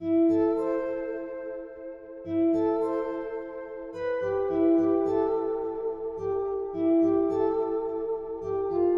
昏昏欲睡的长笛
描述：长笛用于Trap或Hiphop
Tag: 107 bpm Trap Loops Flute Loops 1.51 MB wav Key : Unknown